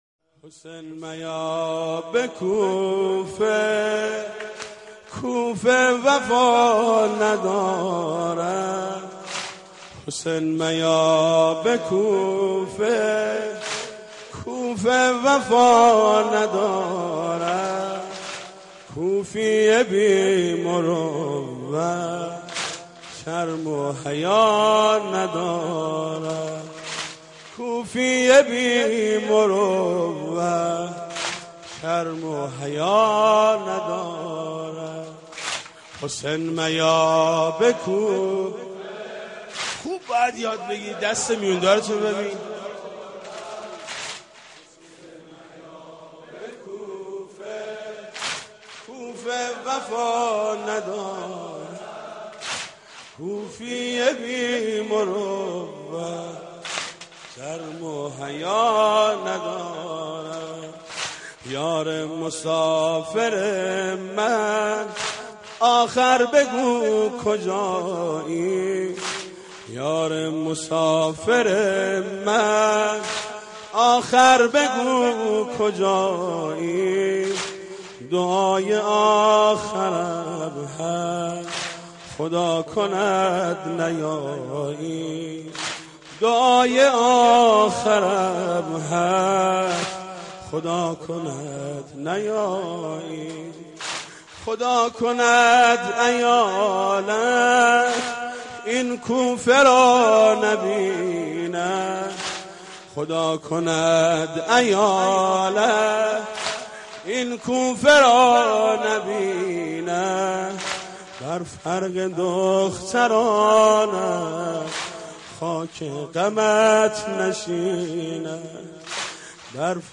Latmiyat / Nawha
selected nawha / latmiyat